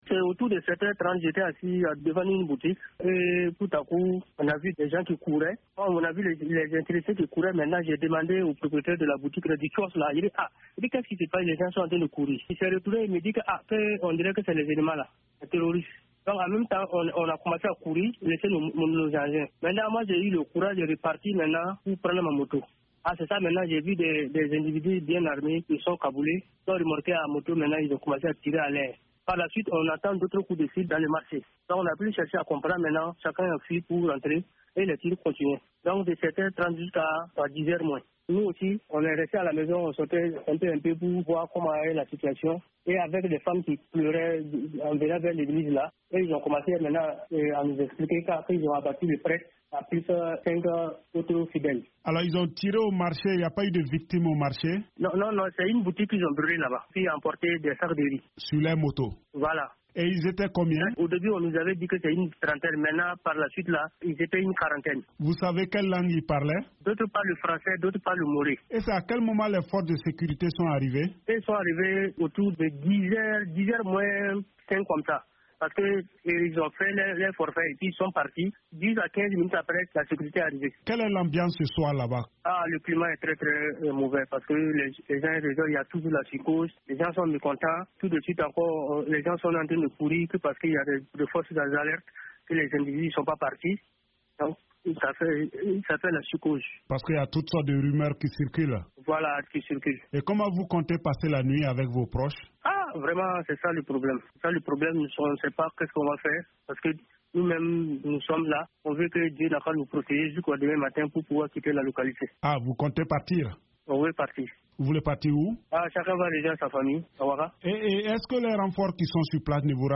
Un témoin décrit l’attaque armée contre une église catholique à Dablo